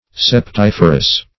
Search Result for " septiferous" : The Collaborative International Dictionary of English v.0.48: Septiferous \Sep*tif"er*ous\, a. [Septum + -ferous: cf. F. septif[`e]re.]